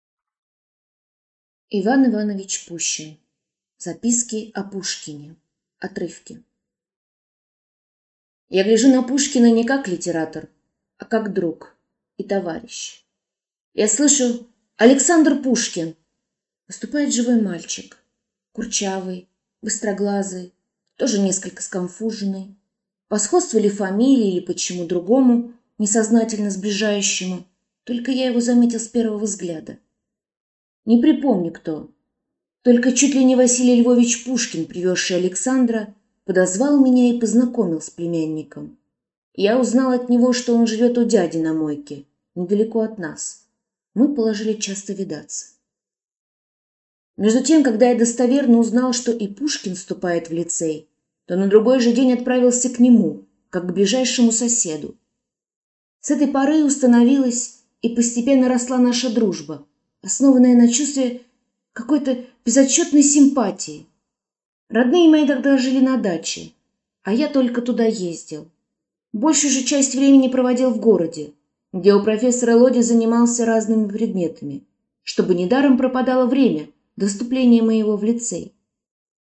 Аудиокнига Записки о Пушкине (Отрывки) | Библиотека аудиокниг